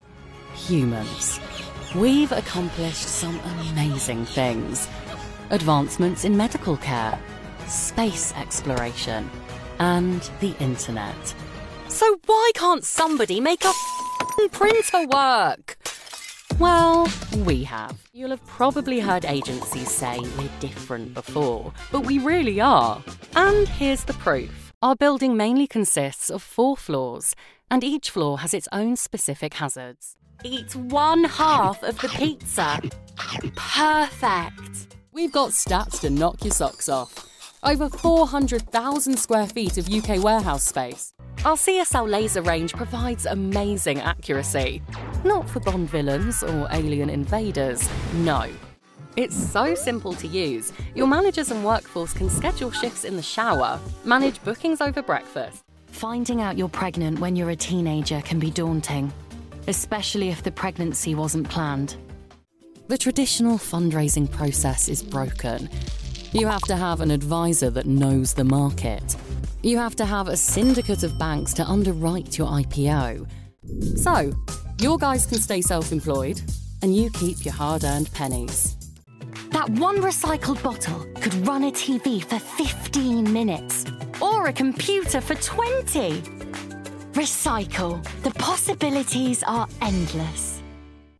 Unternehmensvideos
Mein natürlicher London-Estuary-Akzent, kombiniert mit einem sympathischen Millennial-Charme, ermöglicht es mir, mich nahtlos an verschiedene Projekte anzupassen.
Meine klare, gesprächige Stimme macht komplexe Themen zugänglicher und ansprechender und verwandelt selbst die banalsten Inhalte in etwas Spannendes und Unterhaltsames.
SessionBooth doppelwandige Gesangskabine mit Akustikpaneelen
Lewitt LCT 540 S Mikrofon